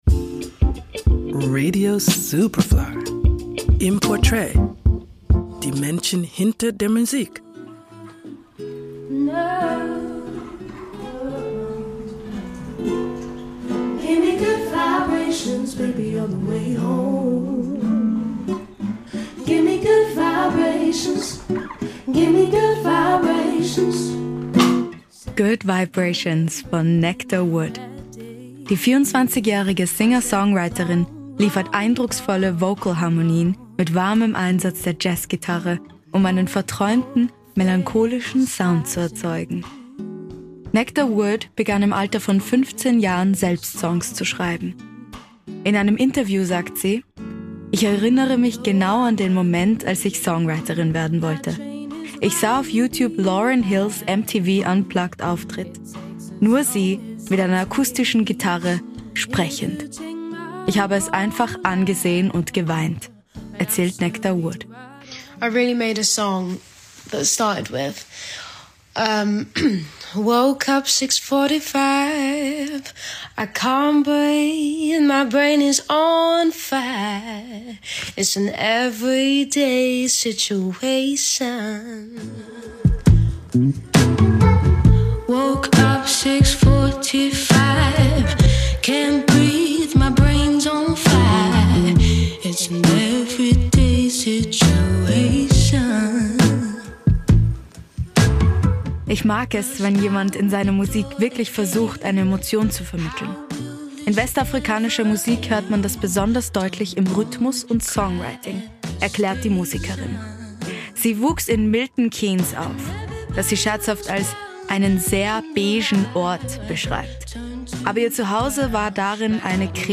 Die 24.-jährige Singer/Songwriterin liefert eindrucksvolle Vocalharmonien mit warmem Einsatz der Jazzgitarre, um einen verträumten, melancholischen Sound zu erzeugen.